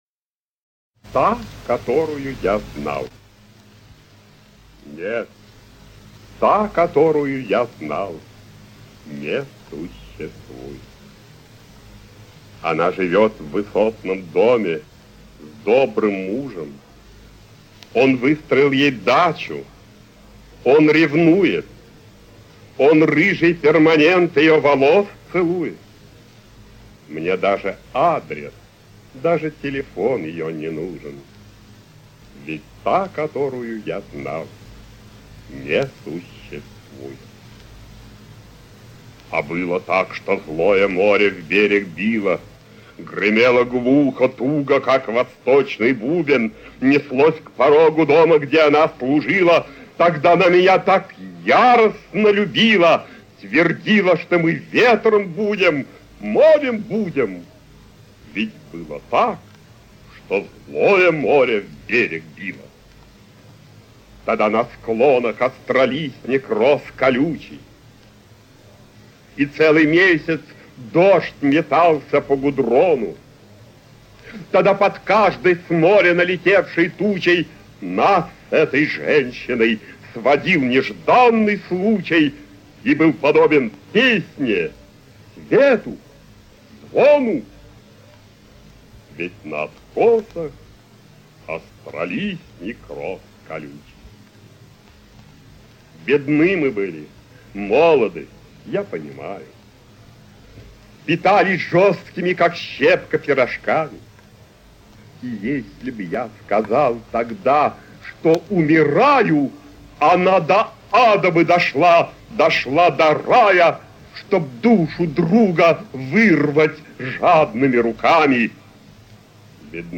1. «Владимир Луговской – Та, которую я знал… (читает автор)» /
vladimir-lugovskoj-ta-kotoruyu-ya-znal-chitaet-avtor